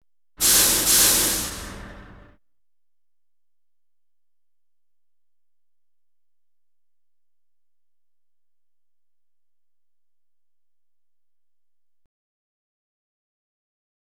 Truck Air Brakes Sound
transport